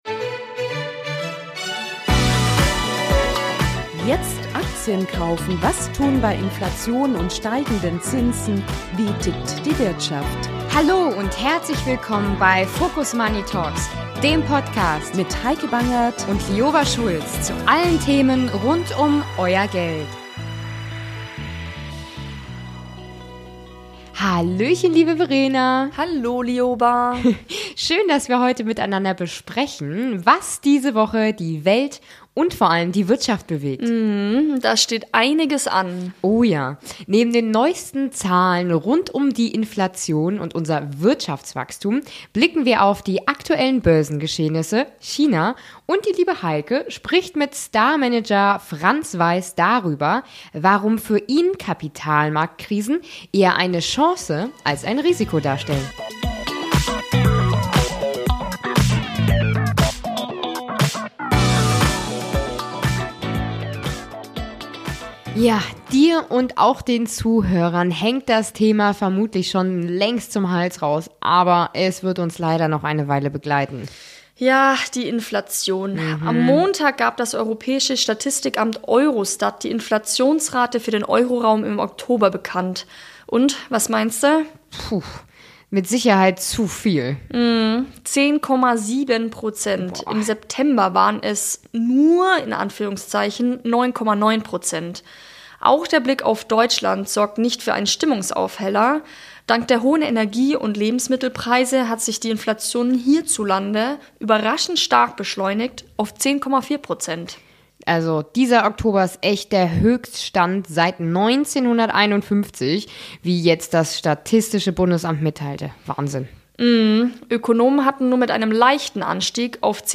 Im Gespräch verrät er seine Strategien in turbulenten Zeiten.